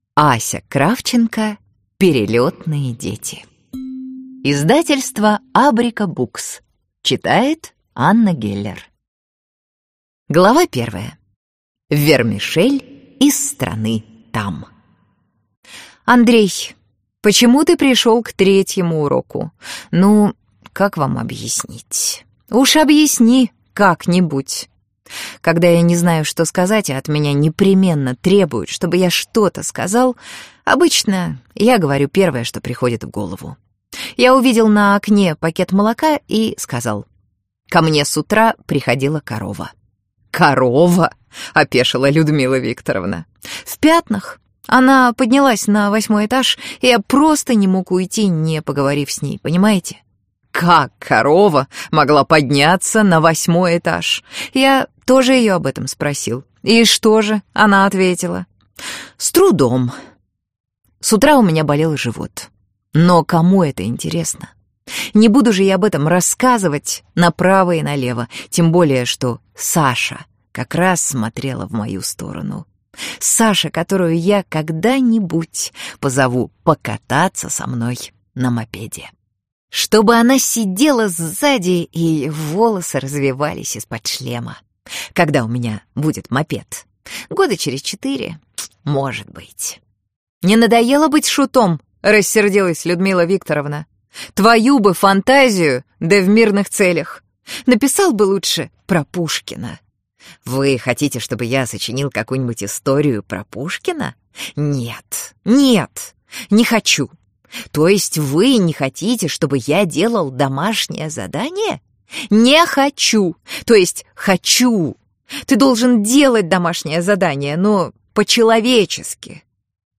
Аудиокнига Перелетные дети | Библиотека аудиокниг